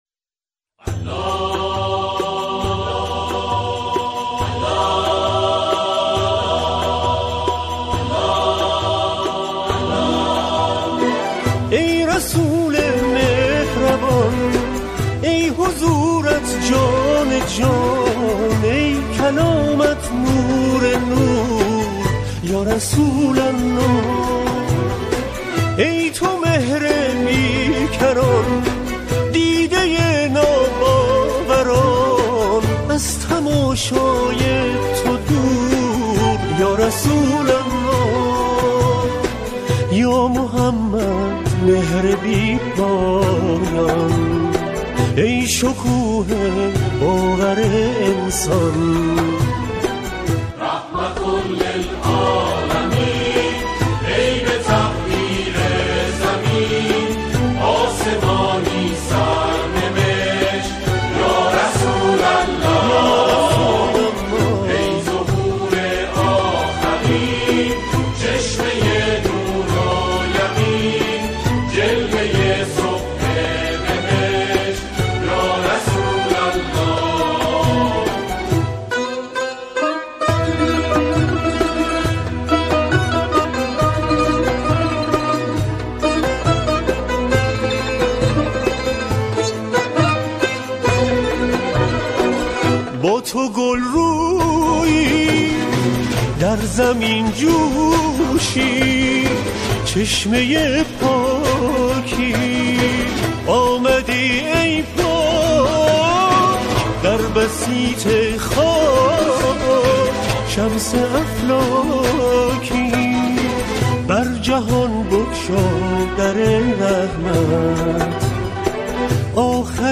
با همراهی گروه کر اجرا می‌کنند